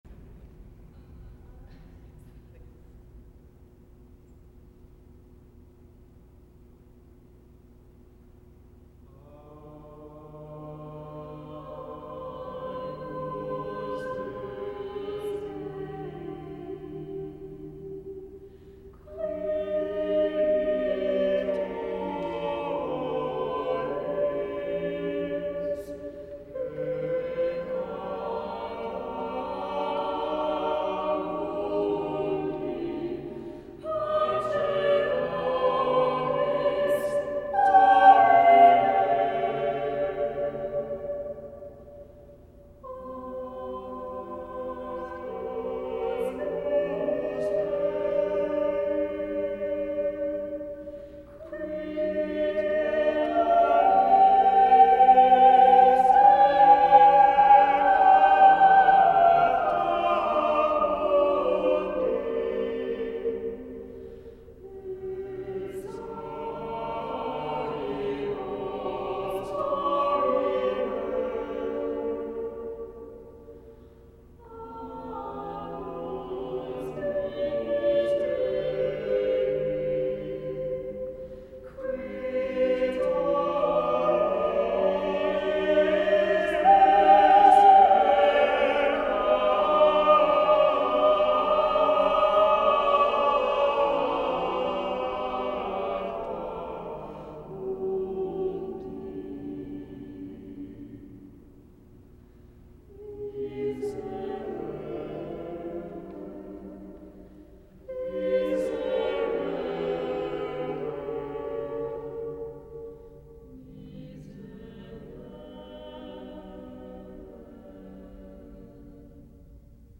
Agnus Dei Read through - 8 voices